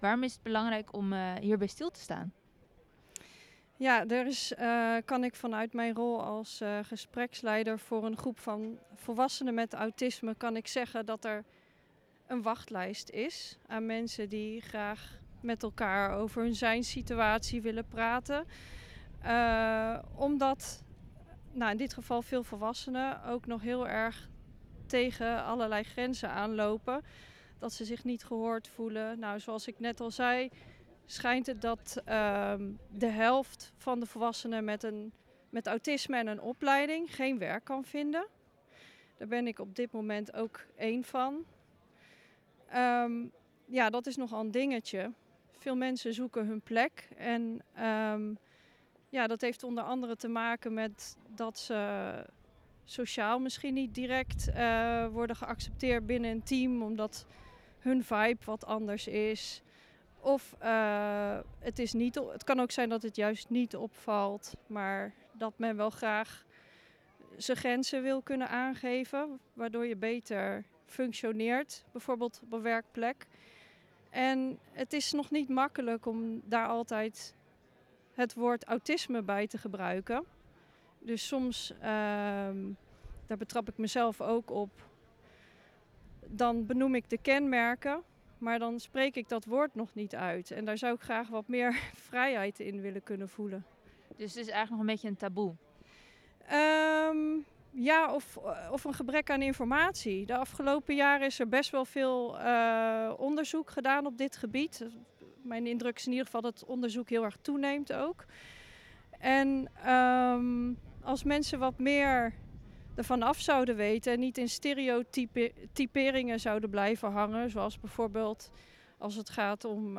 interview-autisme-3.wav